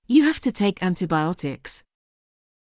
ﾕｰ ﾊﾌ ﾄｩｰ ﾃｲｸ ｱﾝﾃｨﾊﾞｲｵﾃｨｯｸｽ